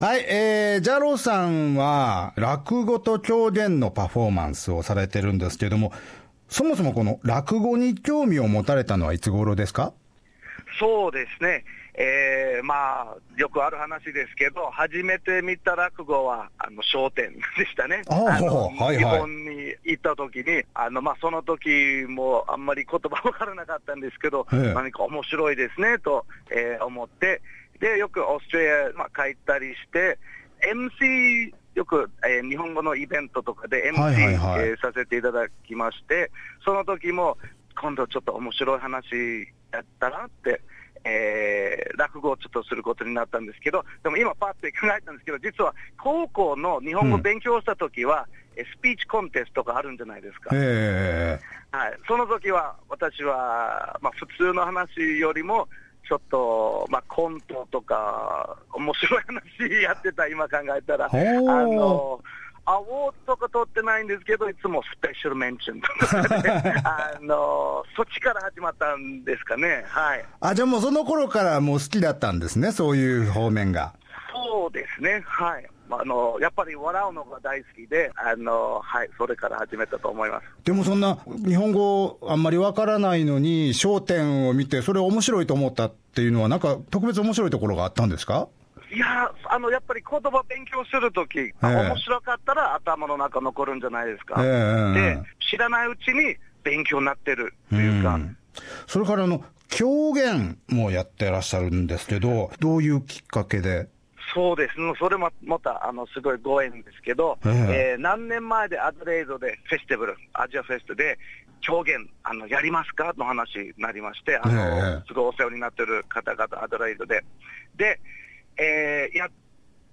チャレンジも笑って楽しく受け入れる、彼の関西弁トークをお楽しみください。